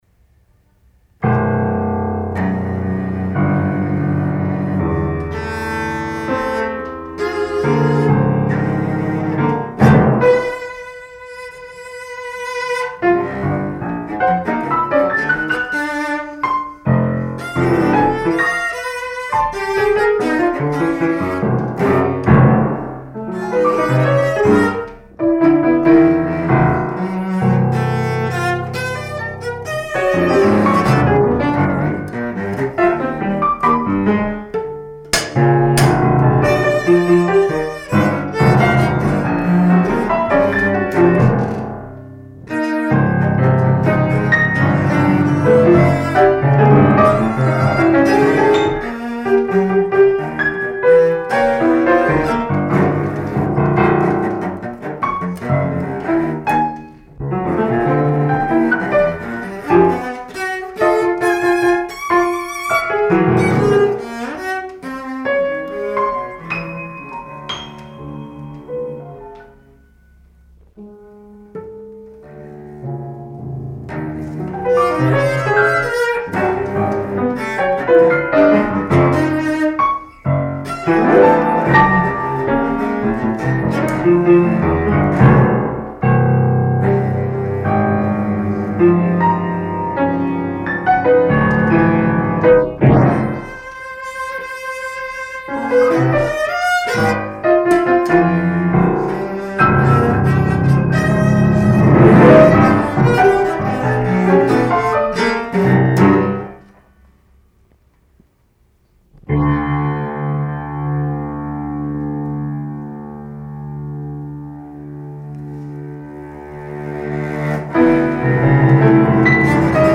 chamber composition
for cello and piano